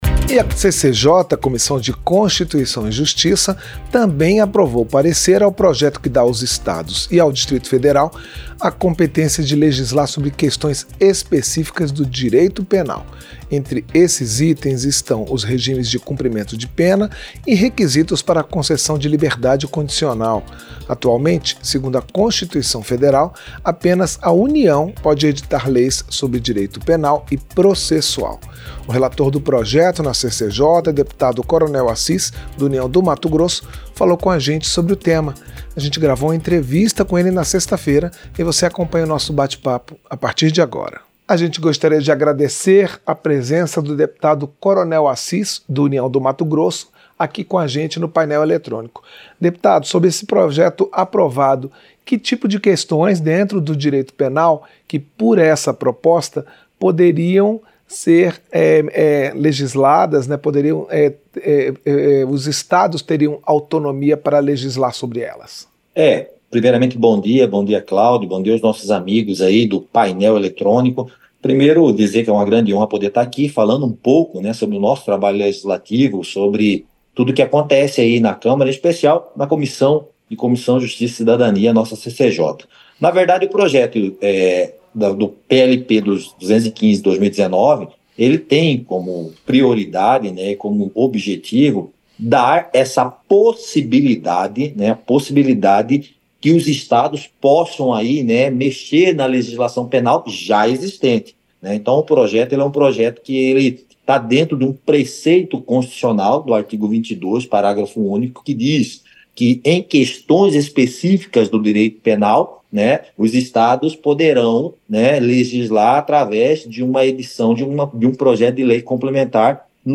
Entrevista Dep. Coronel Assis (União-MT)